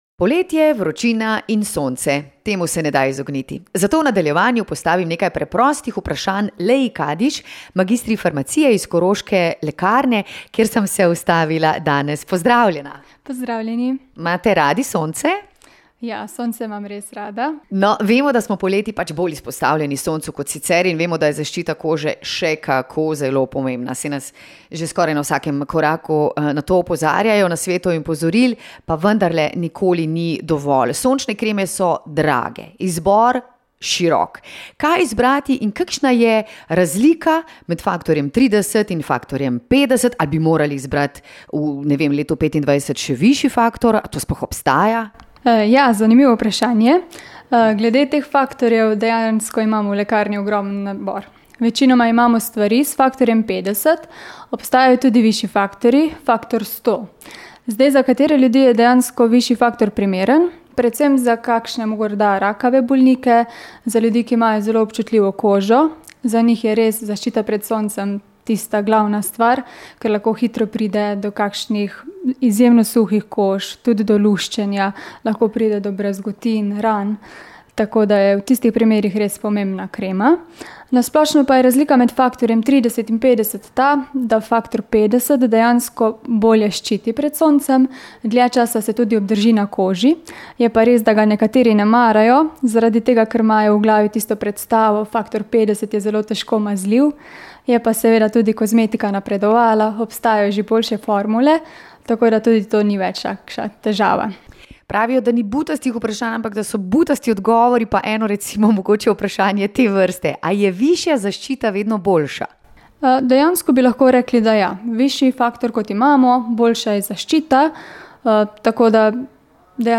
In še več nasvetov za sončenje. V pogovoru z mag.farmacije